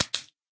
flop2.ogg